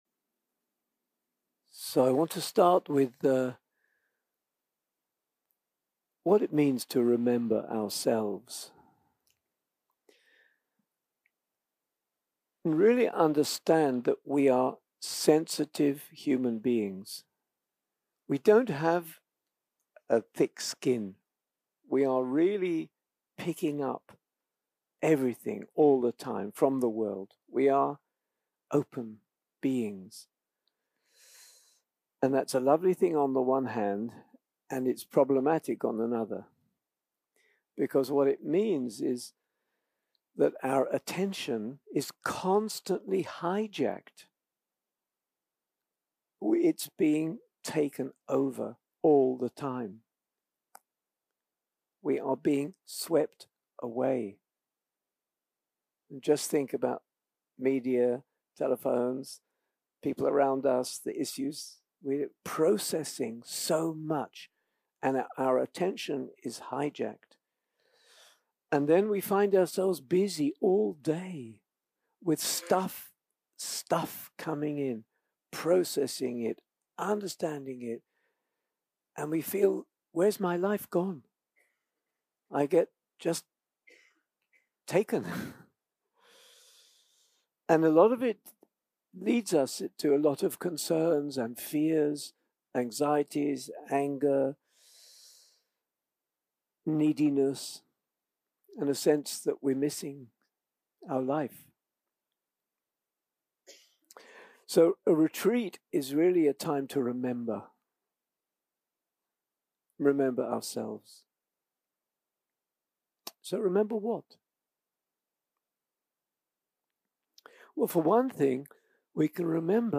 יום 2 - הקלטה 4 - ערב - שיחת דהרמה - The love we give and receive
סוג ההקלטה: שיחות דהרמה